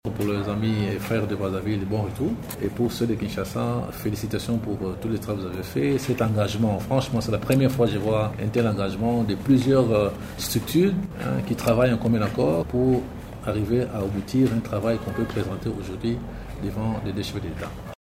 Ecoutez un extrait du discours de Nico Nzau Nzau.